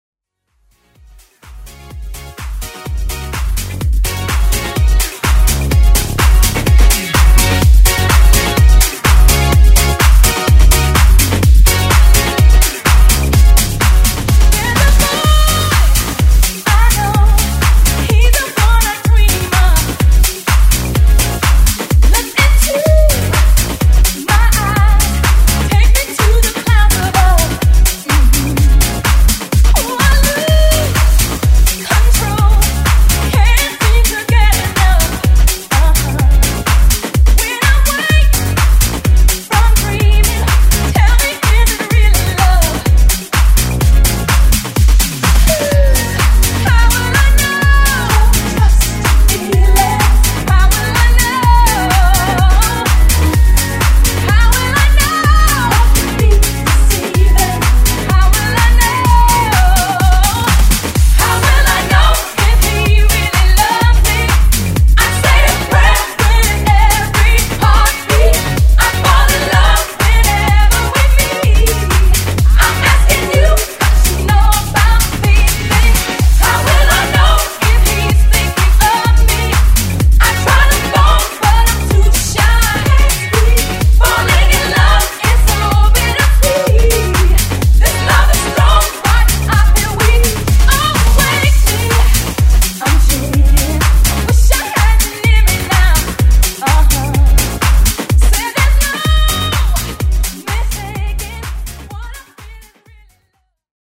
Genres: EDM , HIPHOP , TRANSITIONS AND SEGUES
Dirty BPM: 101-128 Time